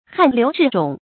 汗流至踵 注音： ㄏㄢˋ ㄌㄧㄨˊ ㄓㄧˋ ㄓㄨㄙˇ 讀音讀法： 意思解釋： 汗出得多，流到腳跟。